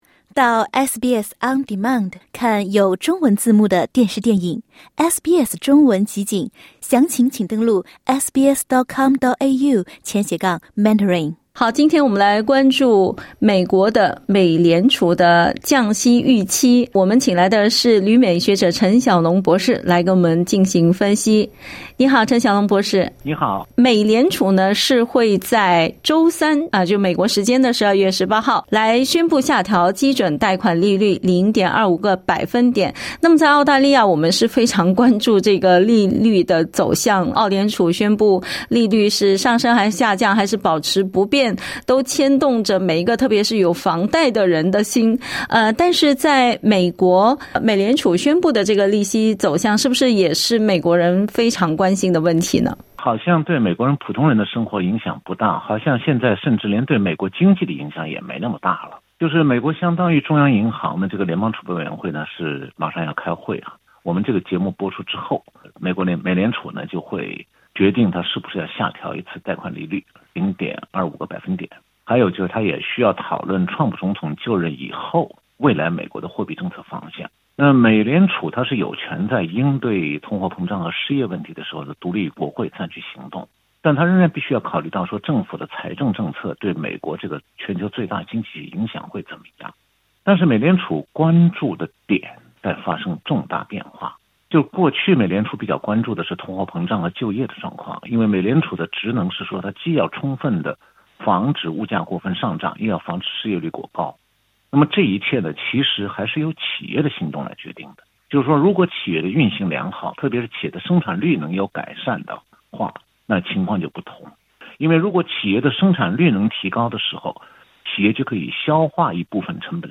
（点击音频收听详细采访） 据法新社和VOA的报道，美联储预计在拜登总统(Joe Biden)任内最后一次会议降息。